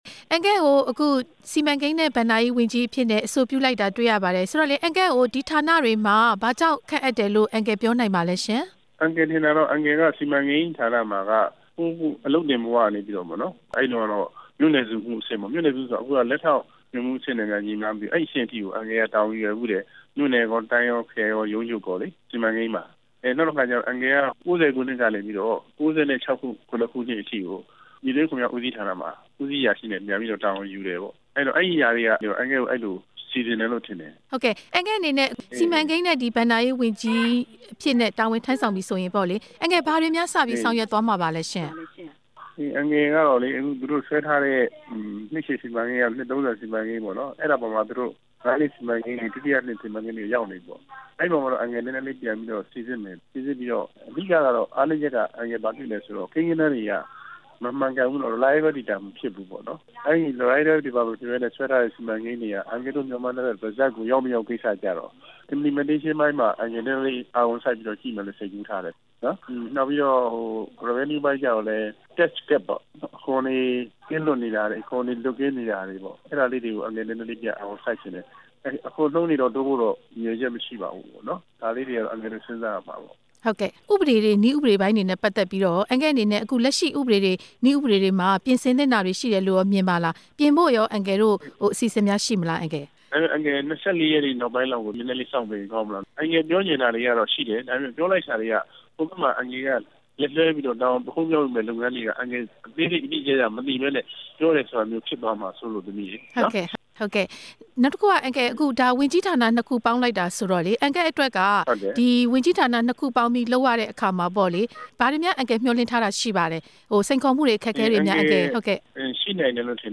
စီမံဘဏ္ဍာဝန်ကြီး လျာထားခံရသူ ဦးကျော်ဝင်းနဲ့ မေးမြန်းချက်